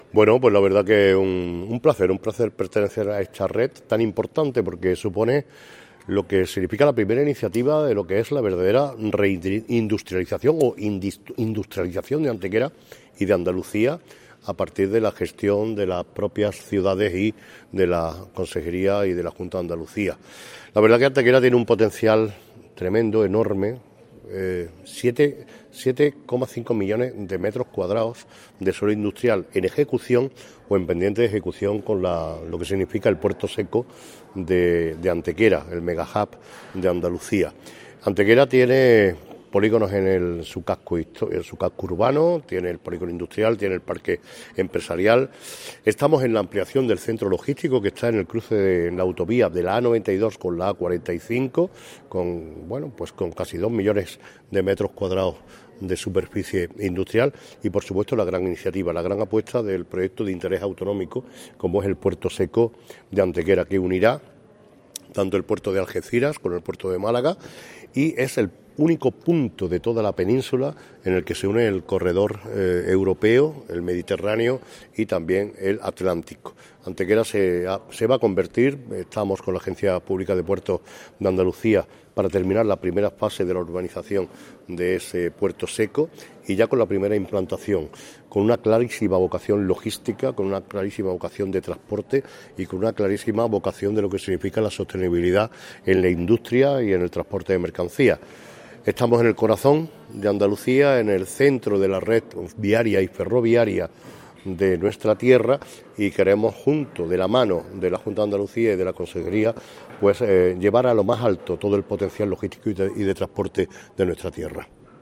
El alcalde Manolo Barón participó en una ponencia en la que puso en valor la importancia de la creación de esta iniciativa y la apuesta por la vocación logística e industrial de nuestra ciudad.
Cortes de voz